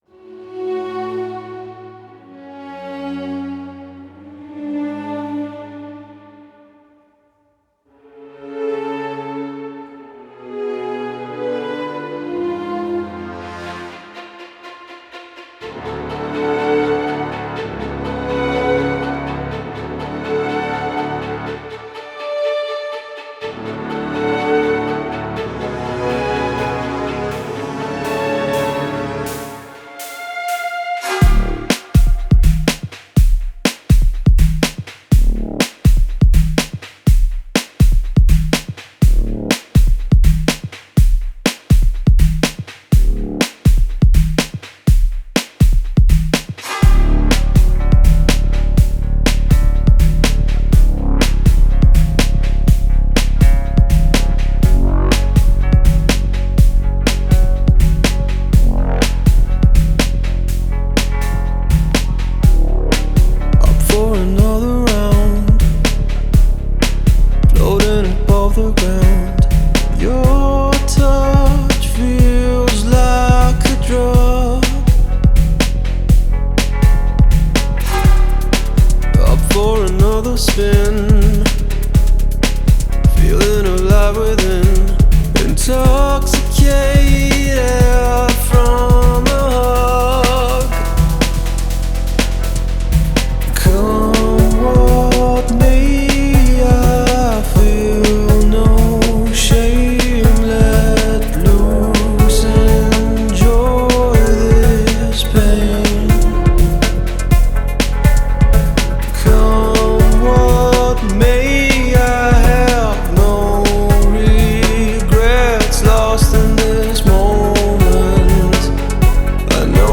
رپ فارس